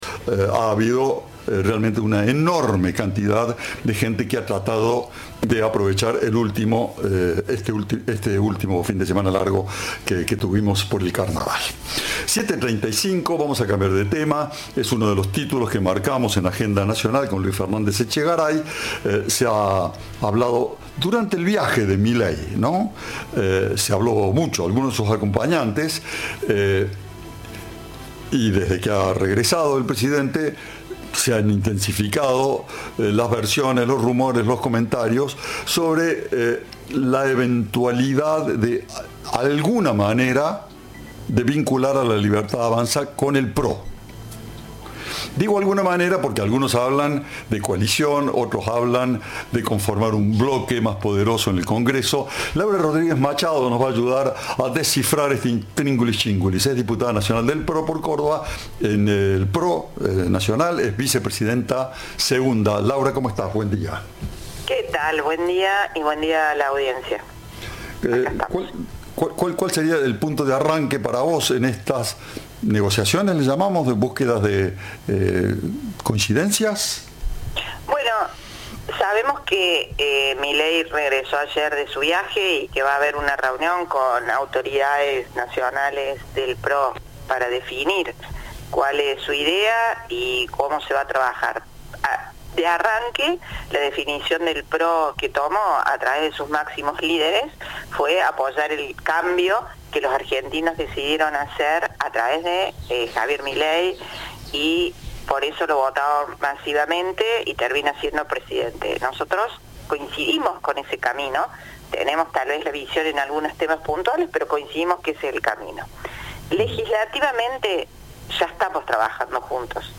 La diputada nacional del PRO dijo a Cadena 3 que el gobernador Llaryora no es una víctima y ratificó el apoyo de su partido al gobierno nacional.